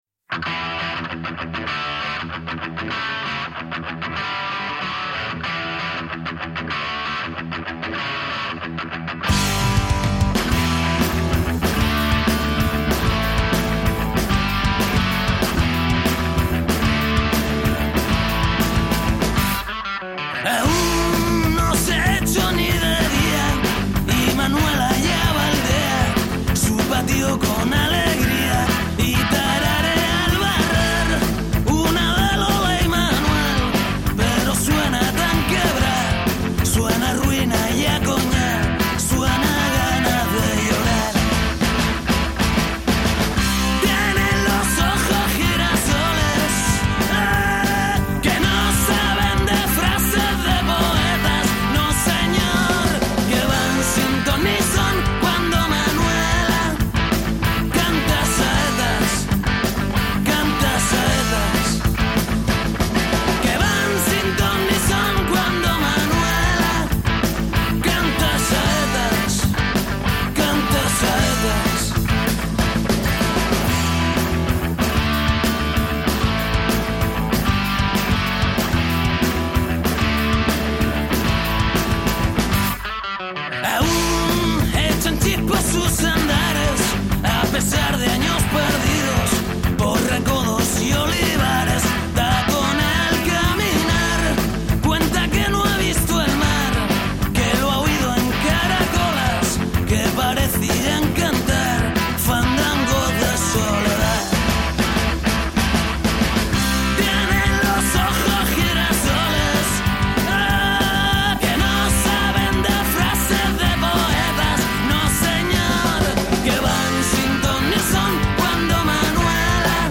canta saetas